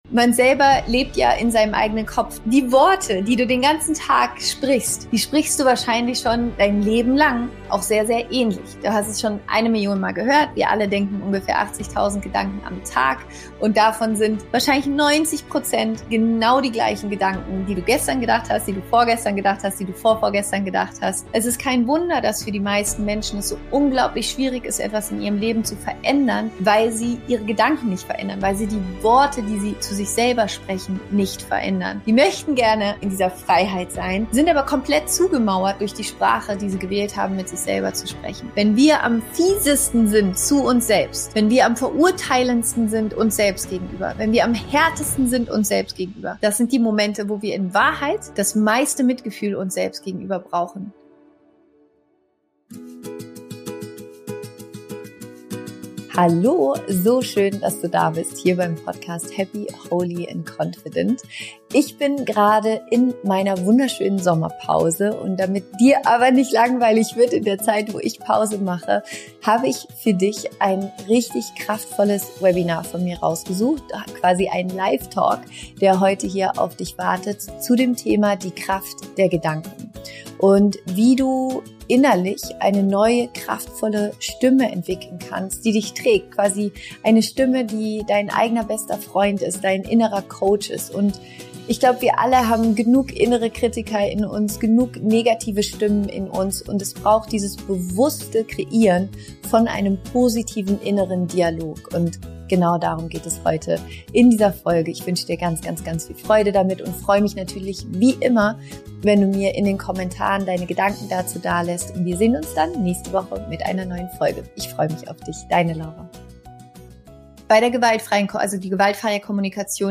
Diese Folge ist ein ganz besonderes Live-Webinar, das ich bisher noch nie öffentlich geteilt habe.